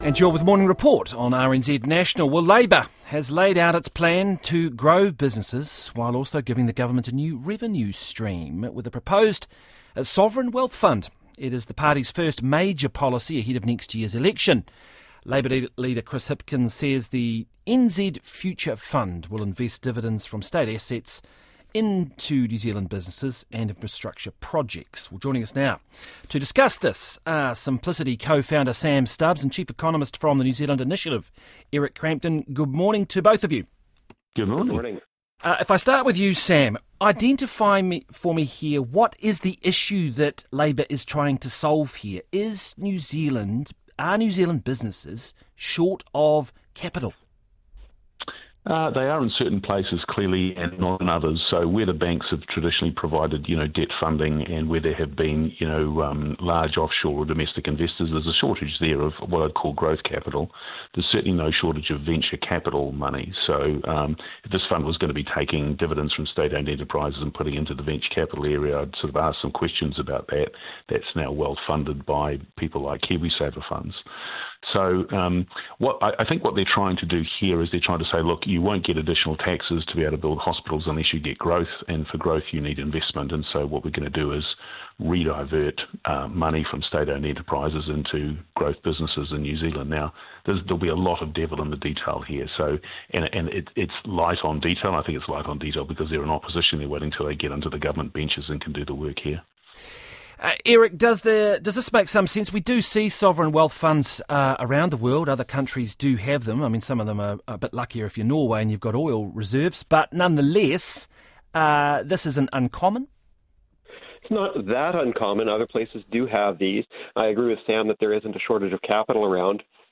talked to Corin Dann on RNZ's Morning Report about Labour's proposed NZ Future Fund